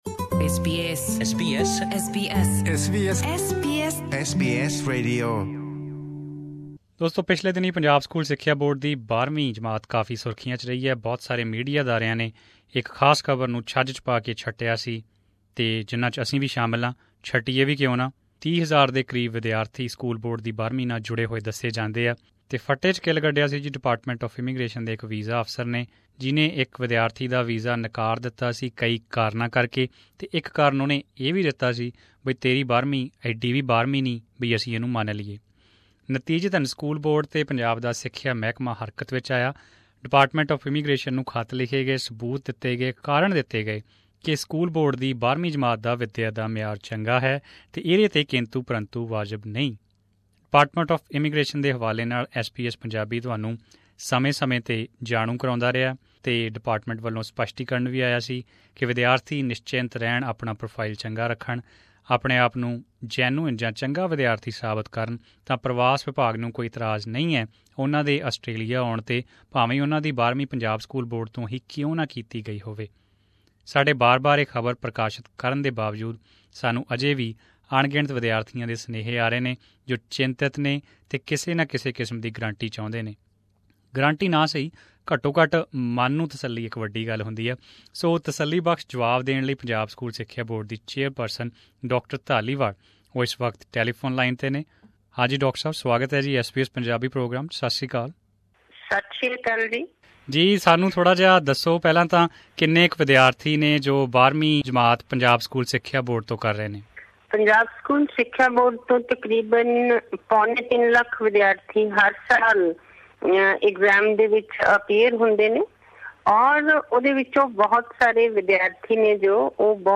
Audio in Punjabi - In this segment, Dr Tejinder Kaur, Chairperson Punjab School Education Board spoke to SBS Punjabi about the Australian Department of Education and Training's decision on 10+2 students enrolled with or passed from PSEB.